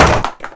wood_break2.wav